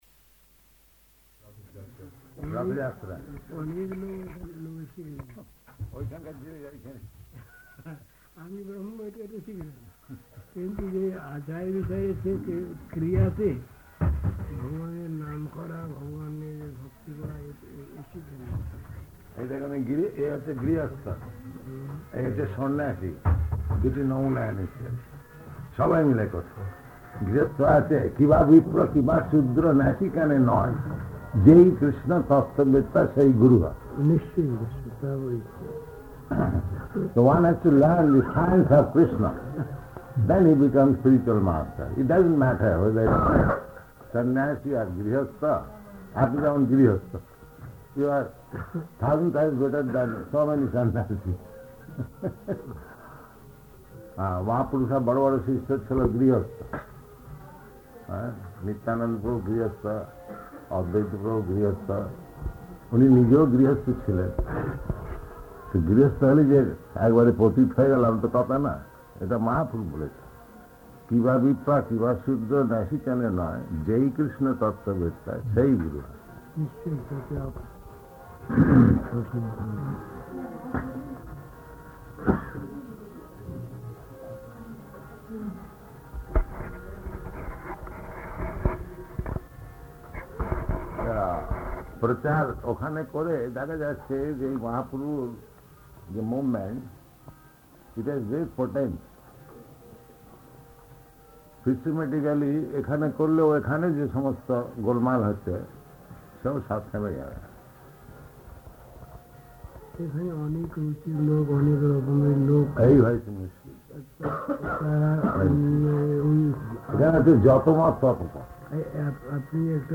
Room Conversation with Hanuman Prasad Poddar [Hindi and English]
Room Conversation with Hanuman Prasad Poddar [Hindi and English] --:-- --:-- Type: Conversation Dated: February 3rd 1971 Location: Gorakphur Audio file: 710203R1-GORAKPHUR.mp3 Prabhupāda: Ratha-yātrā.